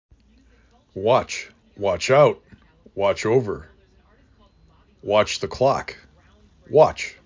5 Letters , 1 Syllable
3 Phonemes
w aw C